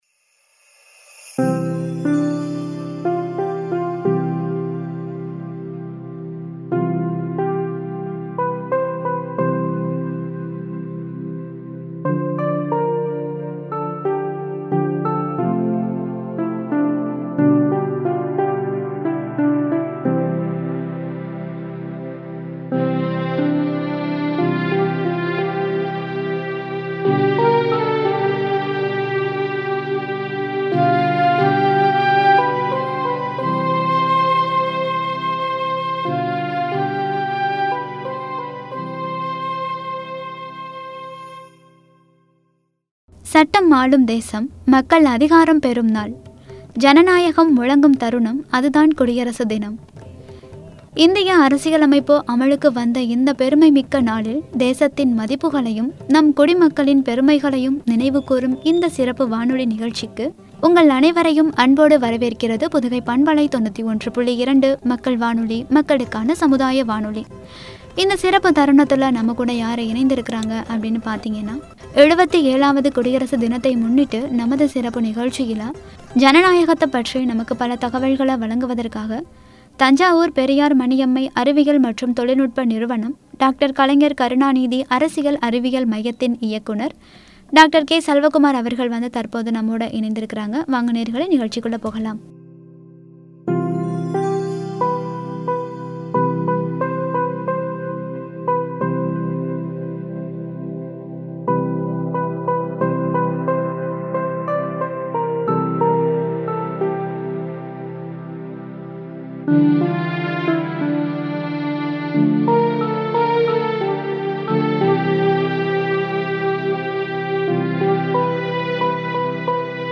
“ஜனநாயகம் போற்றுவோம்”என்ற தலைப்பில் வழங்கிய உரை.